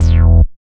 69.07 BASS.wav